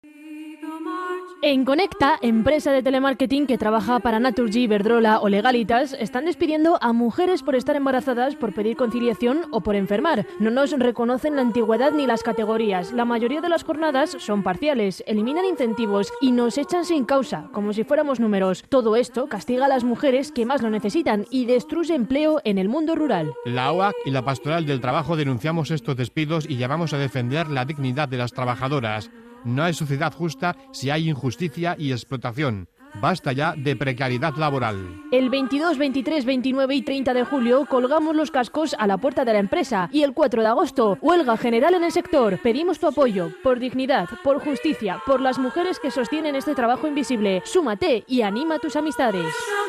En el marco de la campaña de la HOAC, “Cuidar el trabajo, cuidar la vida”, que sitúa la defensa de un trabajo digno como base para una vida digna y una sociedad justa, y en coherencia con la labor de la Pastoral del Trabajo de alzar la voz frente a “estos atropellos a los derechos laborales y de género”, ambas entidades eclesiales se han comprometido a apoyar a la plantilla mediante comunicados, visitas al comité de empresa y una cuña radiofónica emitida en la Cadena SER (Radio Linares).
cuna-radio-hoac-pastoral-del-trabajo-jaen.mp3